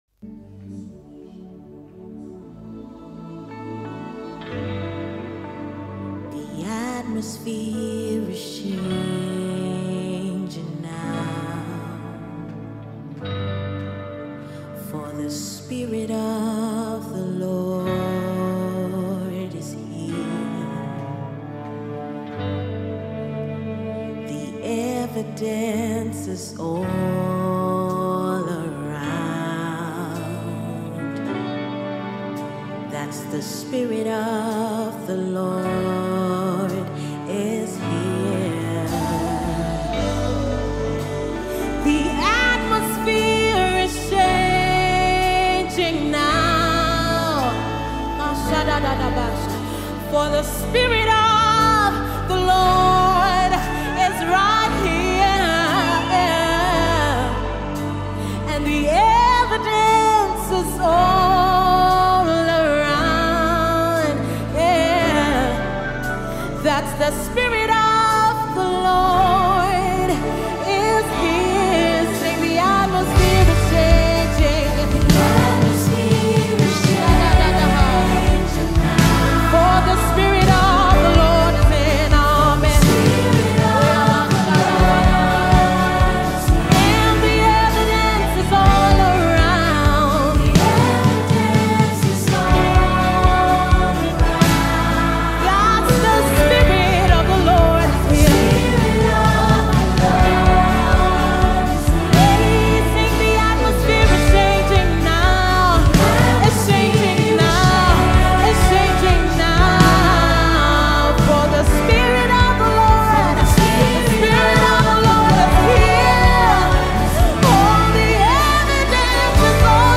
March 28, 2025 Publisher 01 Gospel 0